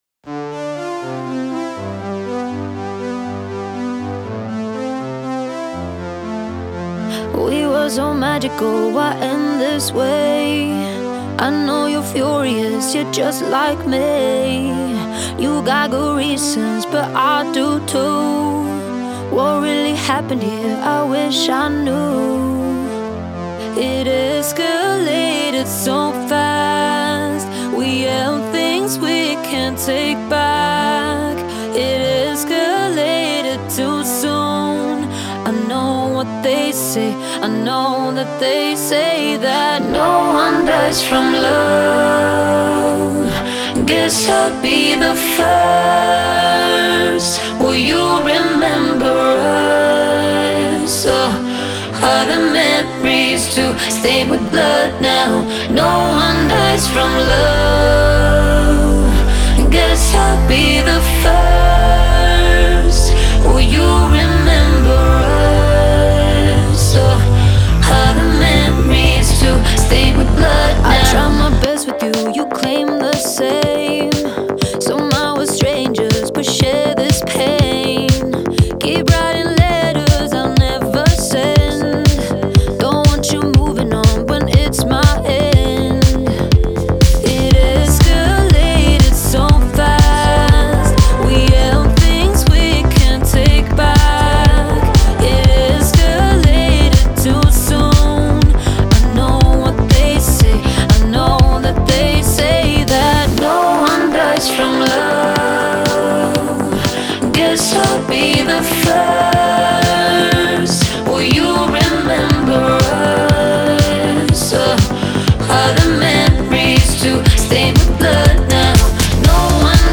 آهنگ سبک پاپ